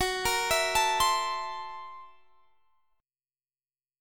Listen to F#7#9b5 strummed